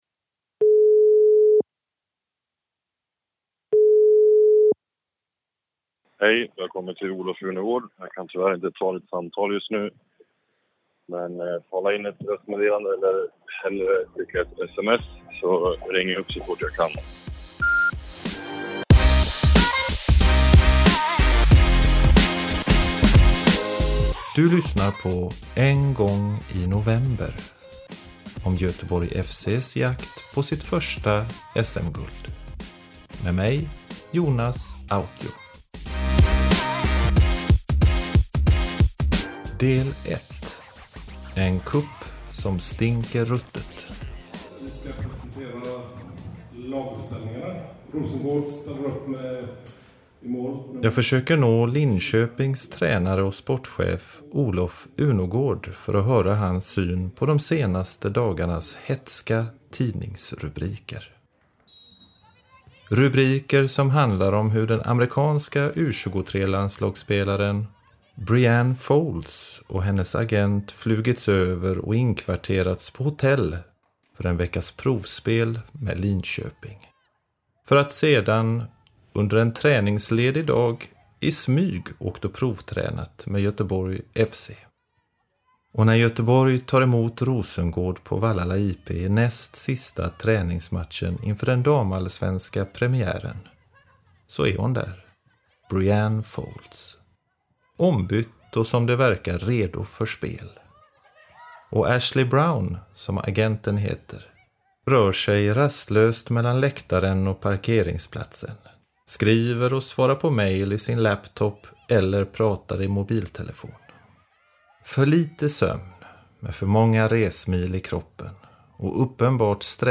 Efter det följer fyra matcher och fyra segrar och Göteborg tycks ha hittat vinnarspåret. Intervjuer